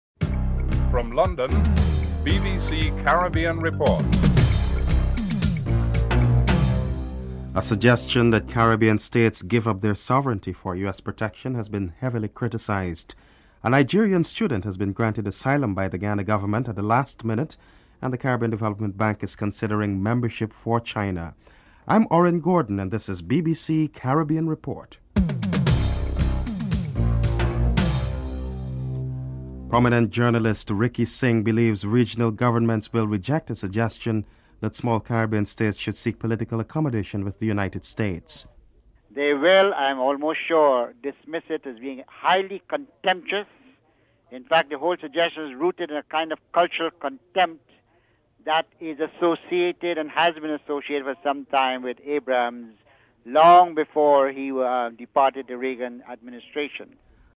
1. Headlines (00:00-00:28)
Prime Minister Edison James is interviewed (08:32-09:18)
Home Office Minister Ann Widdecombe is interviewed (10:41-12:12)